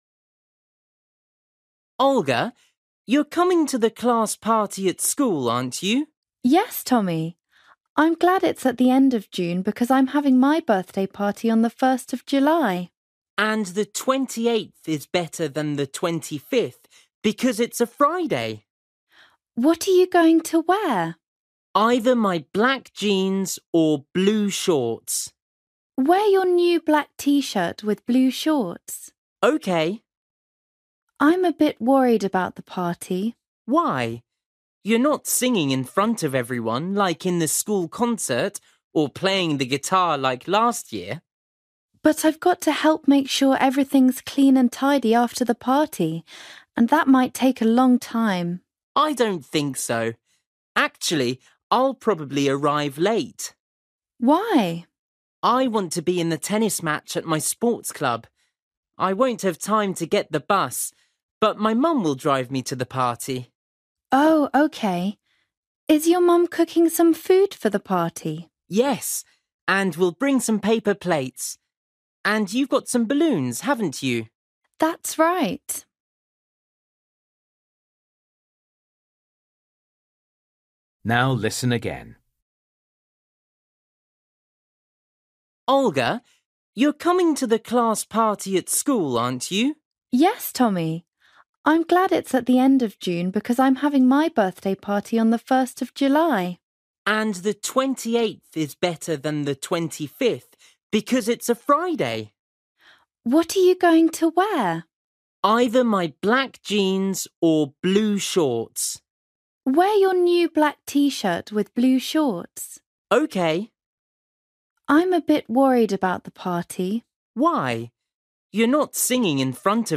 Bài tập trắc nghiệm luyện nghe tiếng Anh trình độ sơ trung cấp – Nghe một cuộc trò chuyện dài phần 4
You will hear Tommy talking to his friend Olga about their class party.